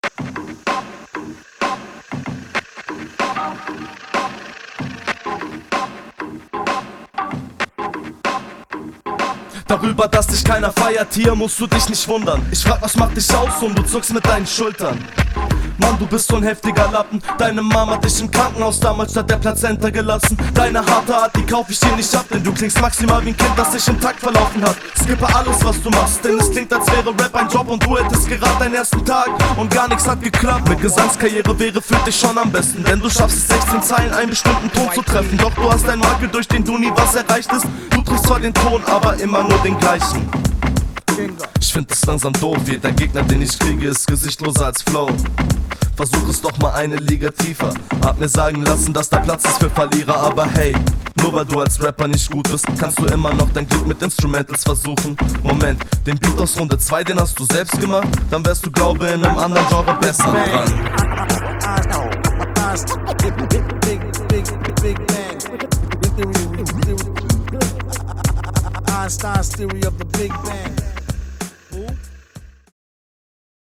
Battle Runden
Hier kommst du ebenfalls recht locker.